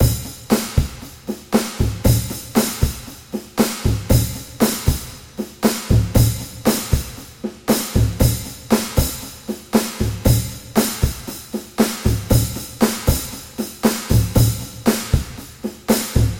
描述：欢乐。
标签： 117 bpm Chill Out Loops Pad Loops 2.76 MB wav Key : F
声道立体声